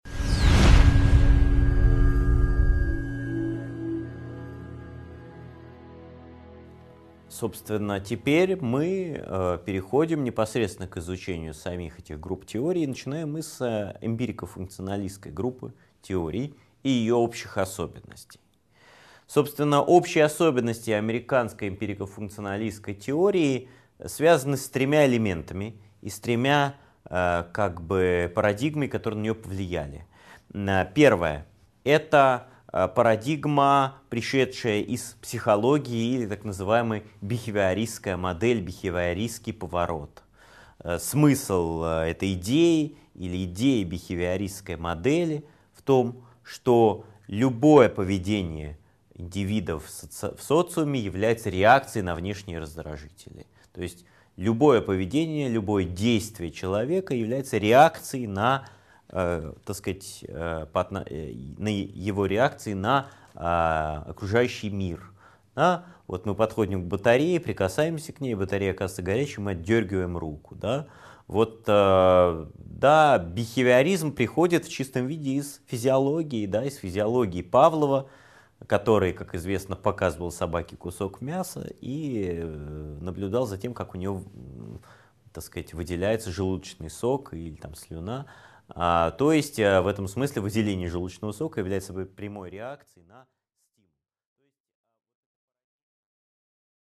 Аудиокнига 5.3 Эмпирико-функционалистская группа теорий: общие особенности | Библиотека аудиокниг